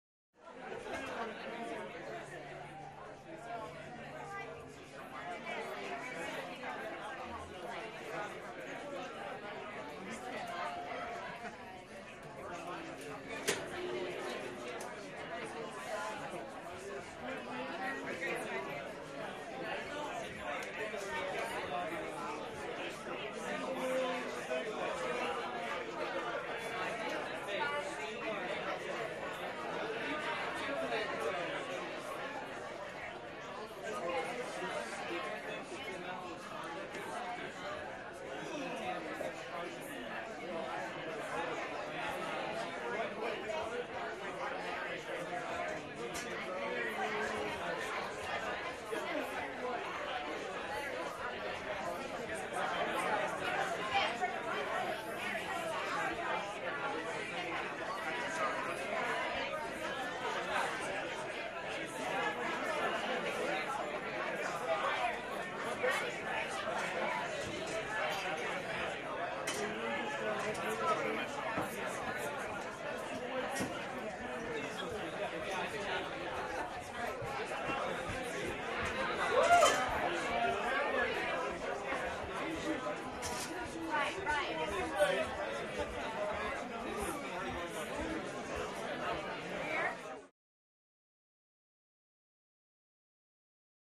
Pub - Busy, Cash Register, Glass Clinks
Pub - Light Walla, People Ordering Drinks, Bartender